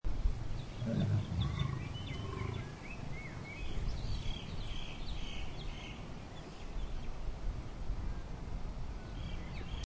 Lions At Melbourne Zoo Bouton sonore